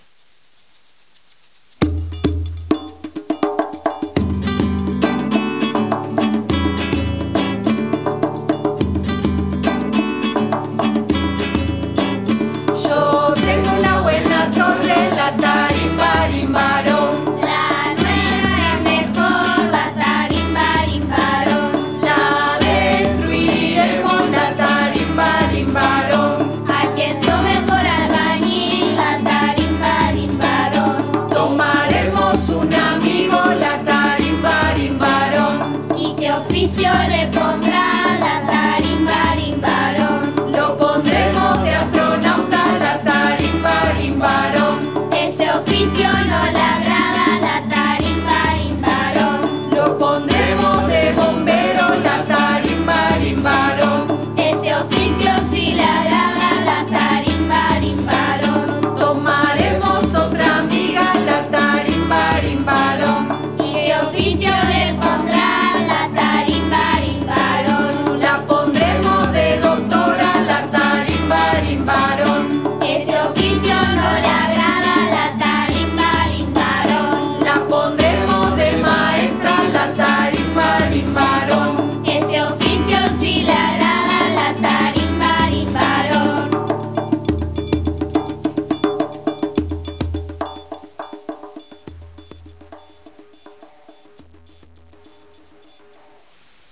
Canción para jugar en dos rondas